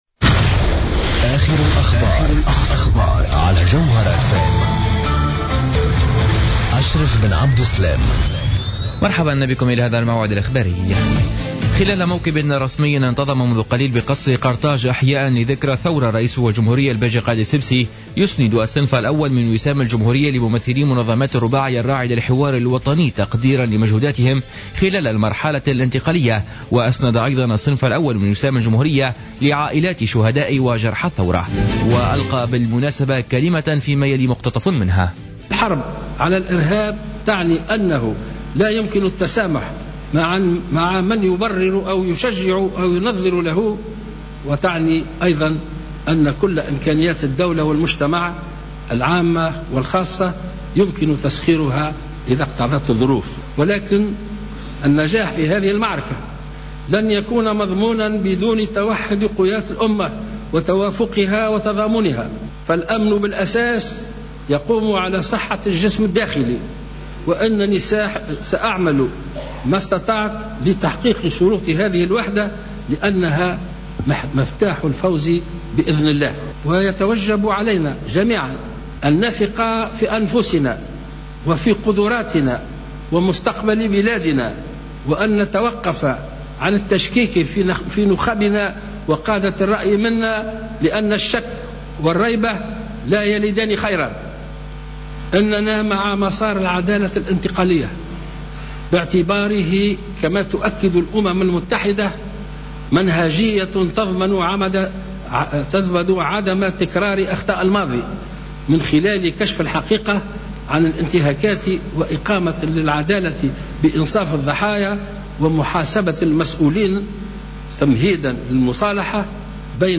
نشرة أخبار منتصف النهار ليوم الاربعاء 14-01-15